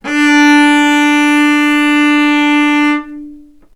vc-D4-ff.AIF